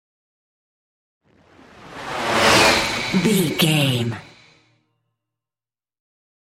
Whoosh bright
Sound Effects
bright
futuristic
tension
whoosh